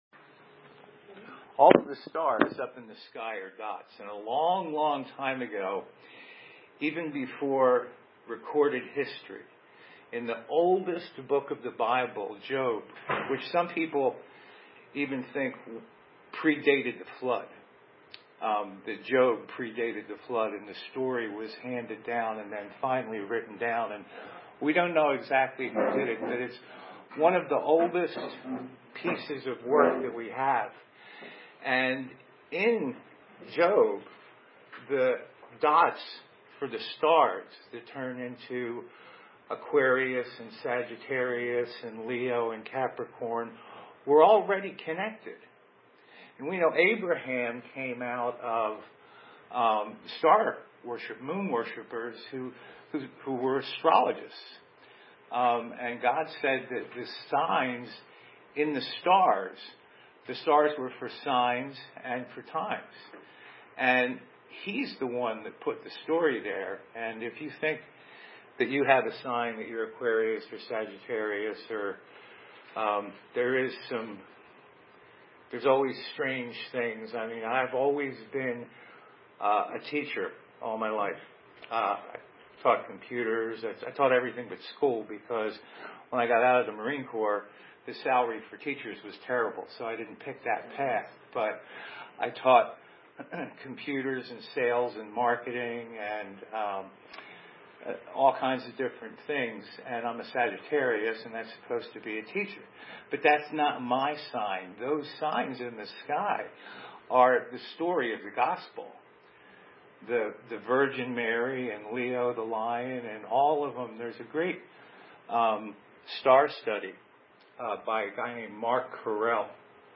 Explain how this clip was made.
The video camera did not record audio. I have uploaded the recording from my phone and the PDF of the slides.